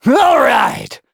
Kibera-Vox_Happy6.wav